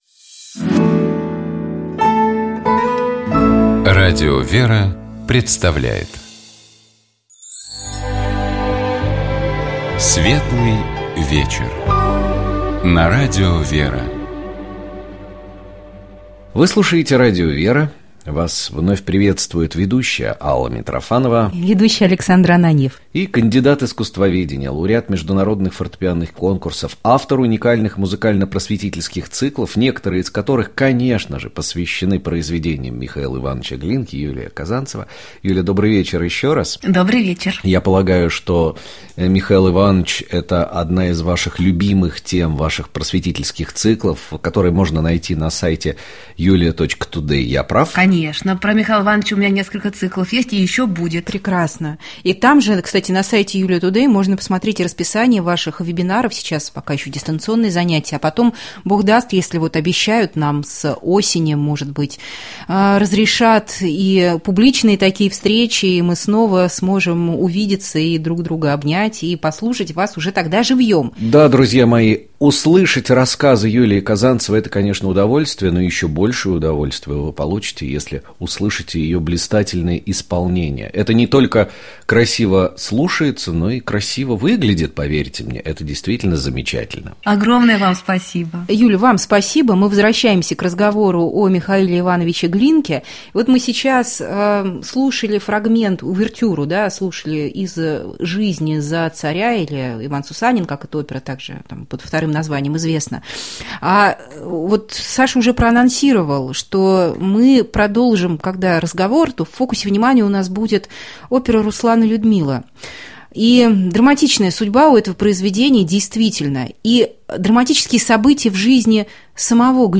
Мы беседовали с кандидатом искусствоведения, пианистом, лауреатом международных конкурсов